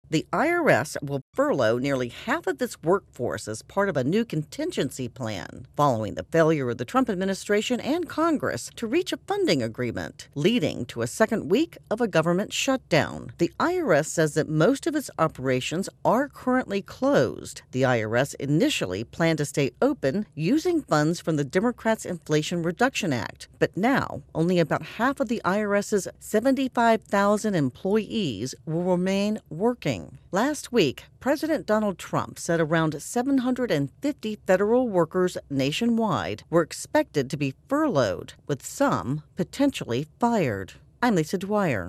reports on a plan to furlough IRS workers.